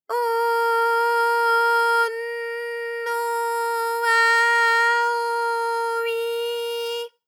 ALYS-DB-001-JPN - First Japanese UTAU vocal library of ALYS.
o_o_n_o_a_o_i.wav